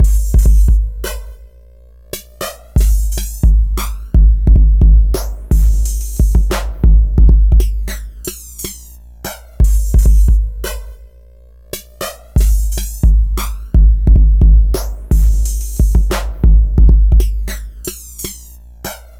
抽象的Hip Hop 808与法兰盘
描述：不同风格的节拍......在21世纪初用电脑制作的一般Midi电脑声音节拍
Tag: 100 bpm Hip Hop Loops Drum Loops 3.23 MB wav Key : Unknown